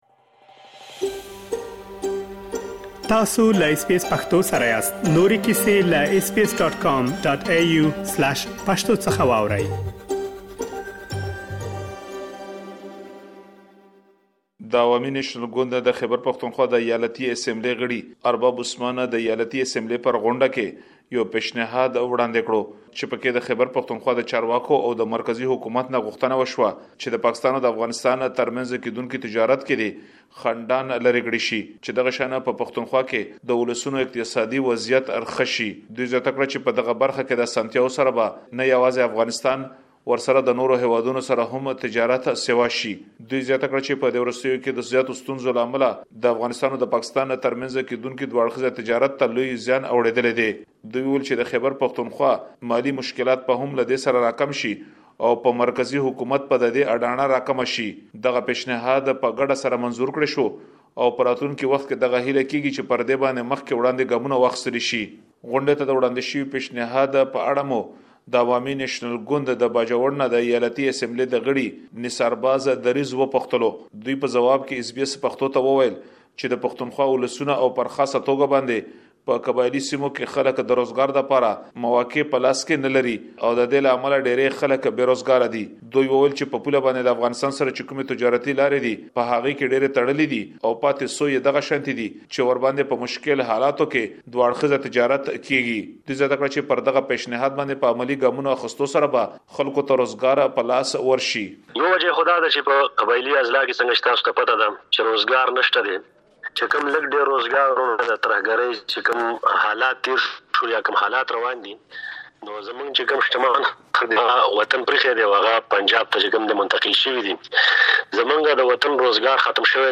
سږ کال د افغانستان او پاکستان ترمنځ سوداګري د سیاسي او امنیتي ستونزو له امله د پام وړ کمه شوې ده. د خیبر پښتونخوا د ایالتي شورا ځینې غړي او سوداګر له حکومته غواړي ترڅو په ډې برخه کې شته ستونزې لرې کړي. مهرباني وکړئ په دې اړه لا ډېر معلومات دلته په رپوټ کې واورئ.